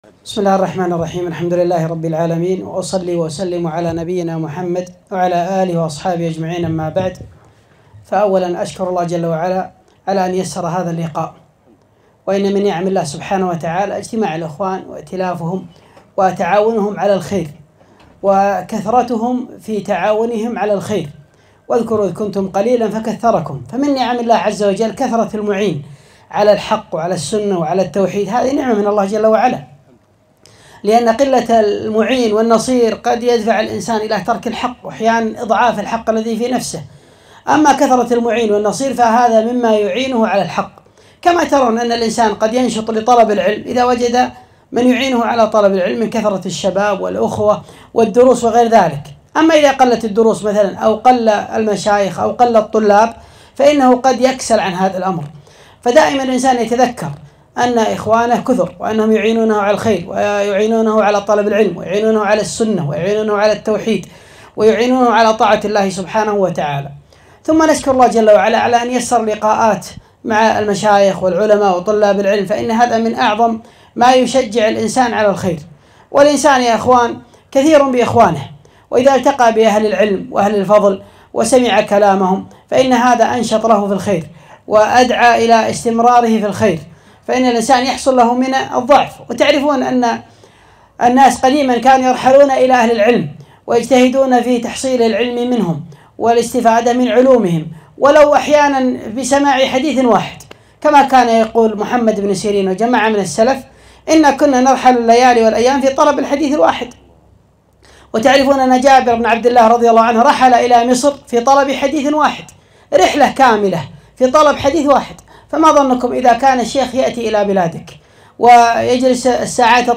كلمة - الحث على الاستفادة من أهل العلم وعدم الاقتصار على شيخ واحد في الطلب والتلقي